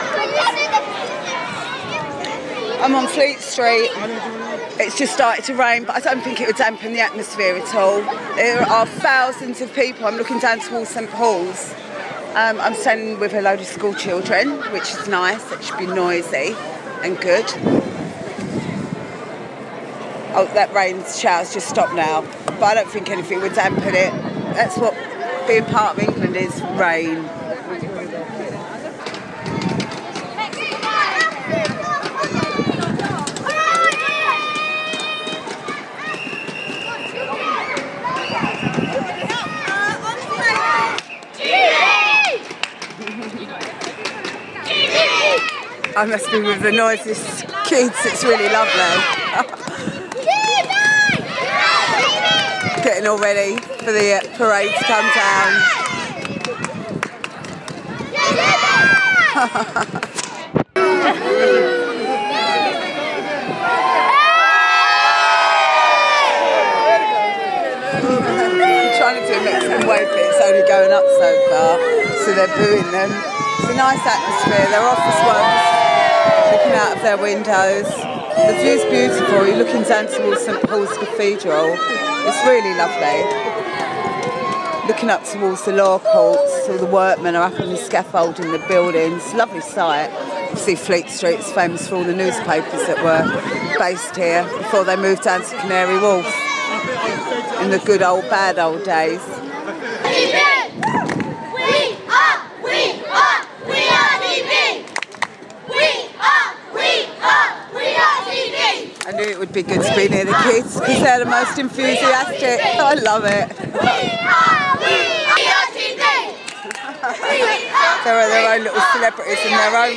Capturing the sounds of the Athletes Parade in Fleet Street